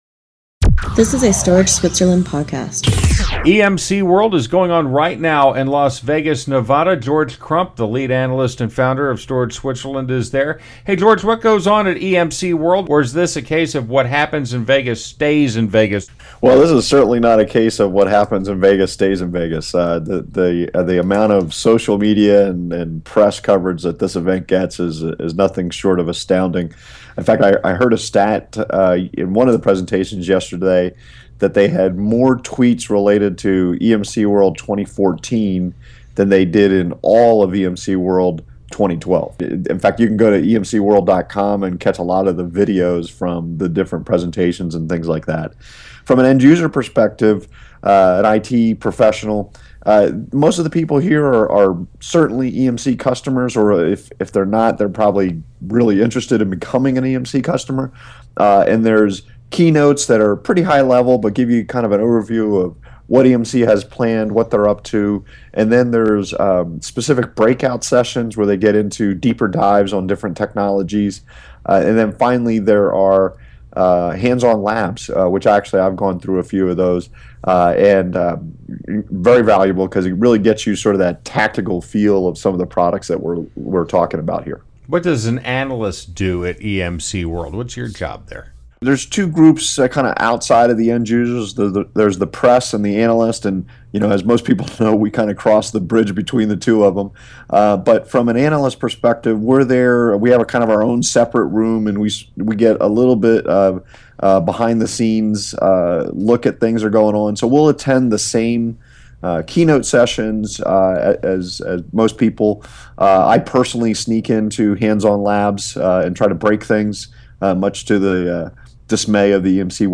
Storage Switzerland is at EMCworld in Las Vegas.